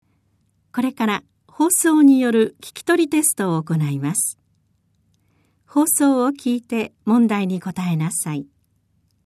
テスト音声